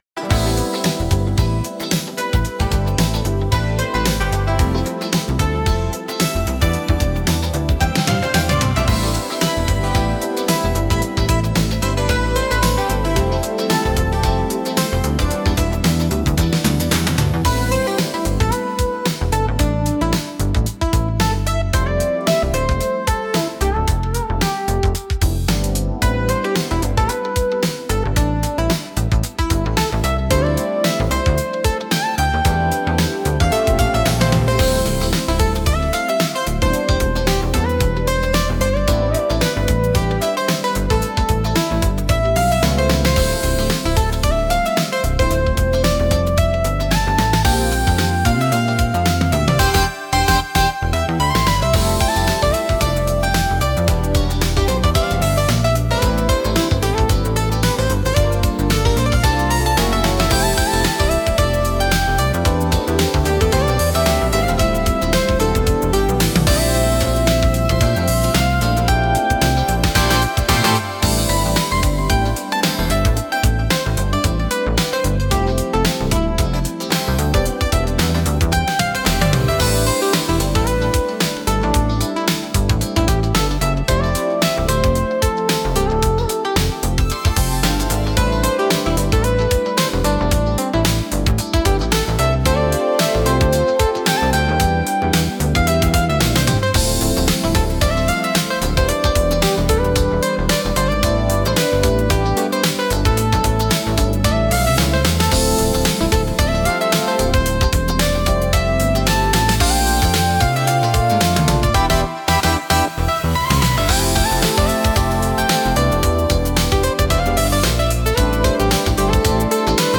シティポップは、1970～80年代の日本で生まれたポップスの一ジャンルで、都会的で洗練されたサウンドが特徴です。